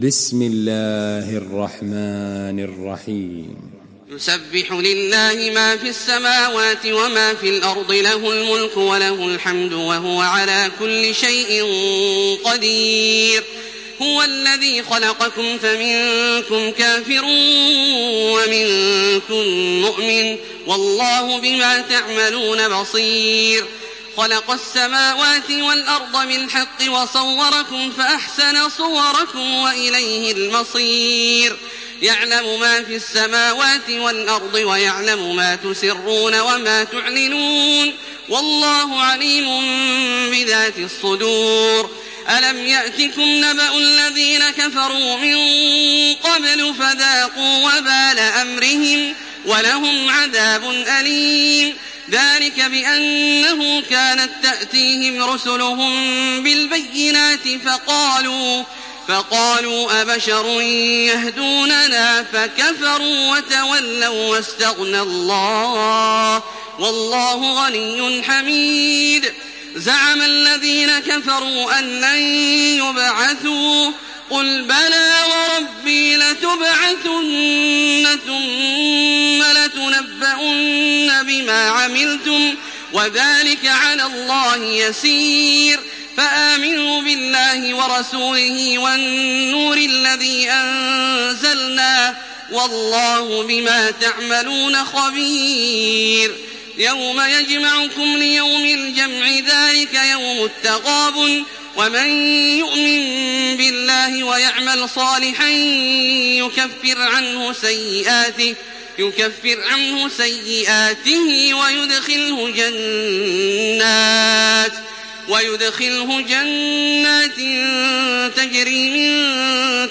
سورة التغابن MP3 بصوت تراويح الحرم المكي 1428 برواية حفص
مرتل